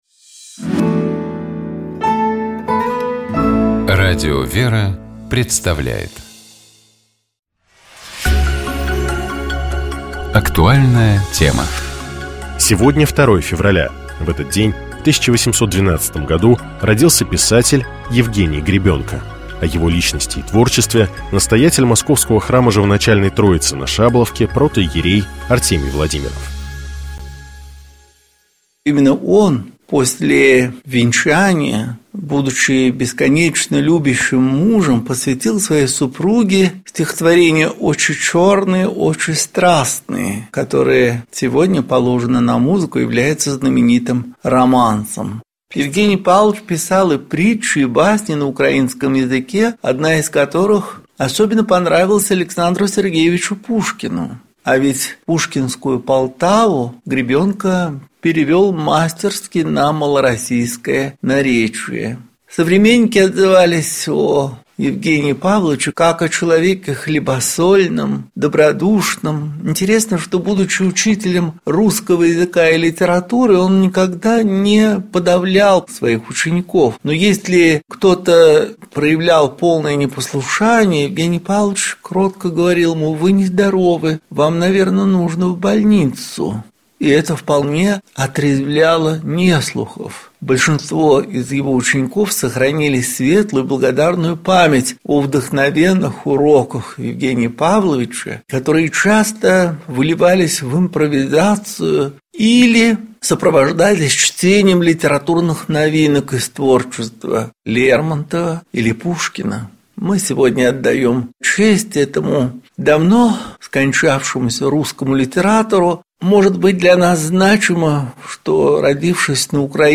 Богослужебные чтения Скачать 01.02.2026 Поделиться Здравствуйте!